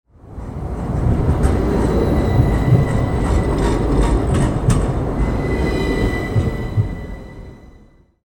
Streetcar Passing Very Close Rattling Rails Sound Effect
Authentic trolley passing very close rattling rails sound effect captures the metallic clatter of wheels on tracks as the wagon moves through urban streets. This urban trolley sound immerses listeners in realistic city ambience.
Streetcar-passing-very-close-rattling-rails-sound-effect.mp3